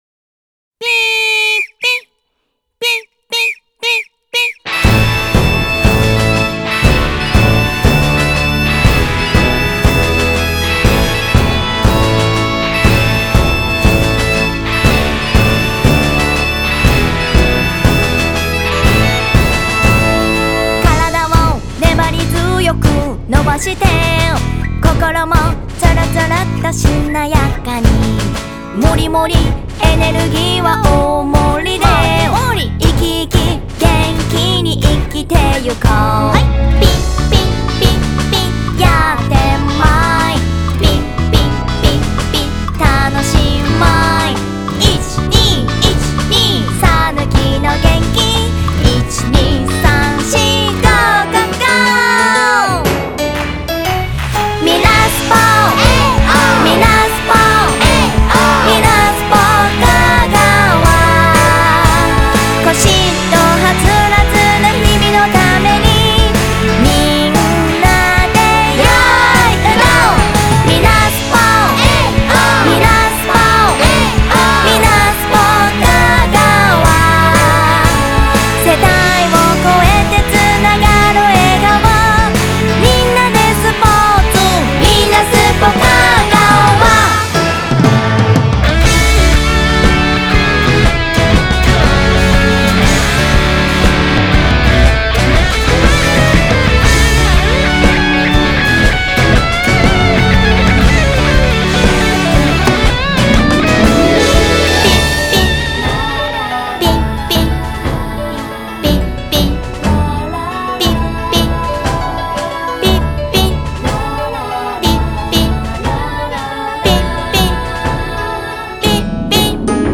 オリジナル体操音源